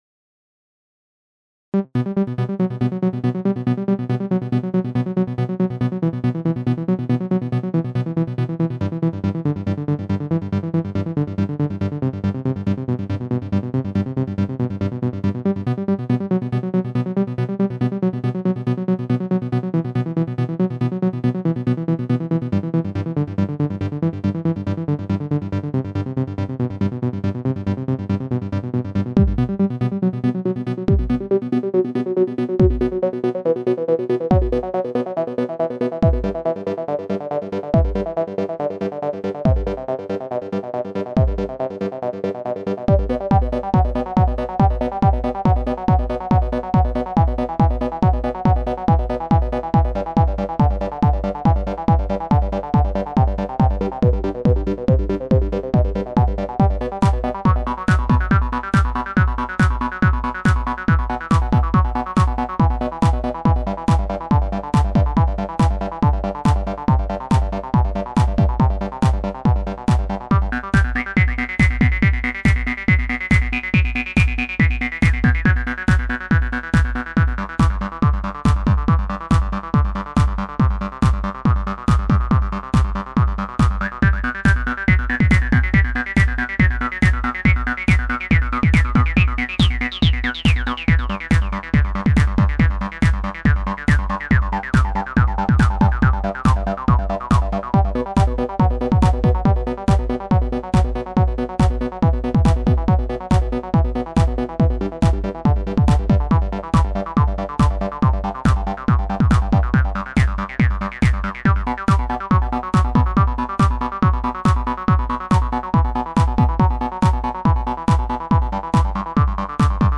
intensive trance mix
• Jakość: 44kHz, Stereo